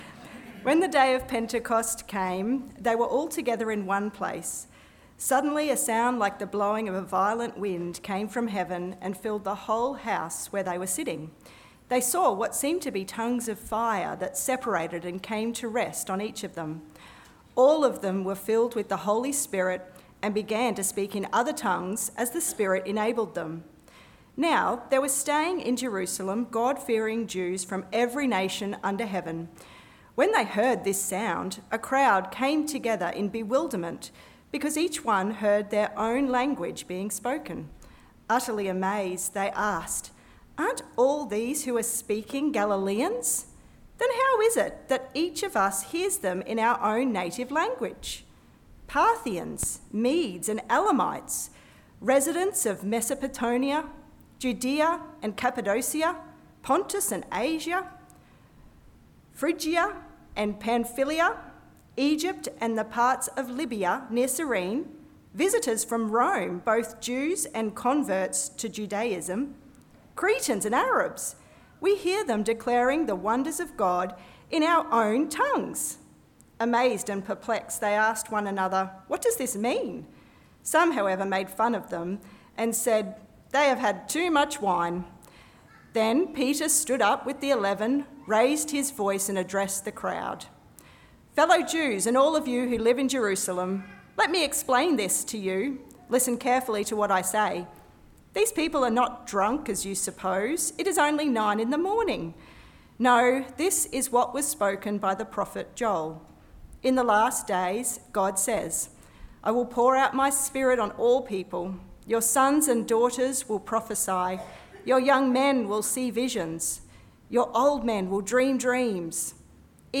Sermons – Cairns Presbyterian Church